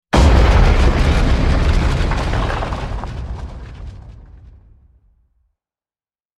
Ground Smash Sound Effect
Heavy rock or massive object smashing into the ground with intense impact and deep rumble.
Ground-smash-sound-effect.mp3